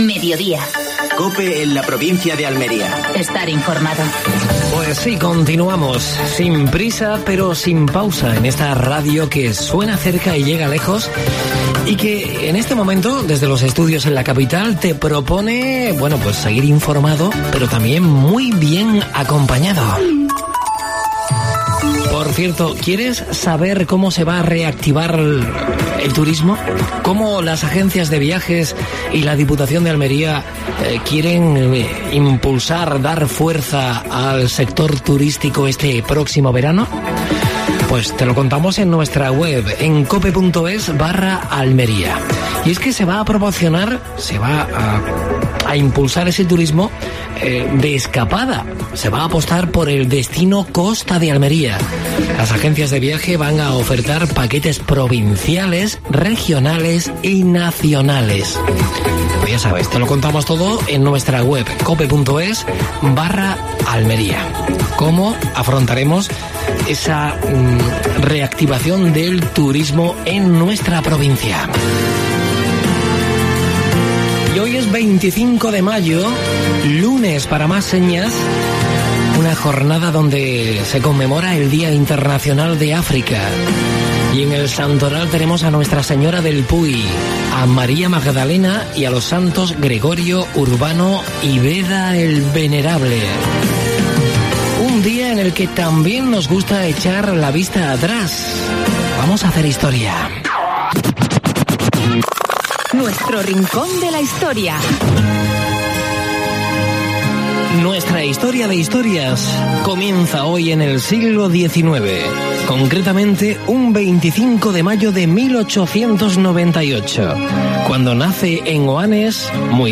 AUDIO: Actualidad en Almería. Entrevista a Ismael Torres (alcalde de Huércal de Almería).